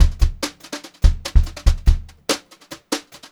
144GVBEAT3-L.wav